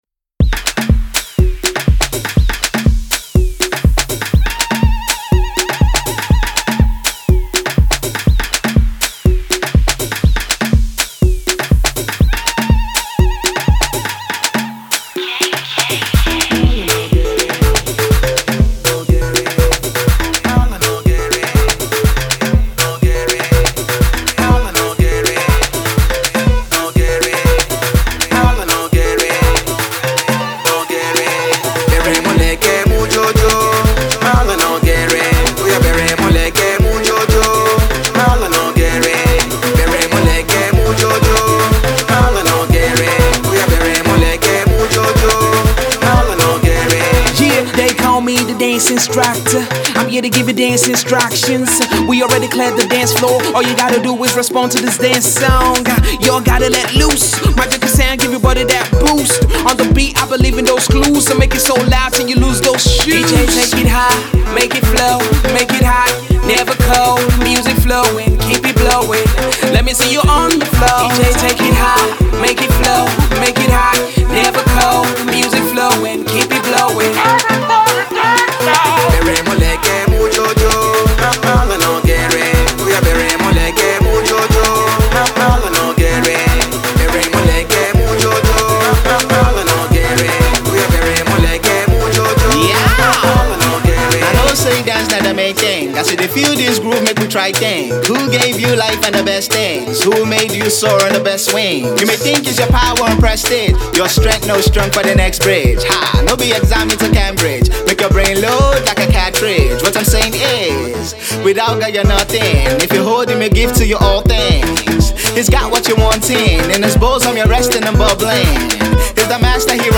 sonically suave dance joint
a savvy new joint sizzled with southern African rhythms.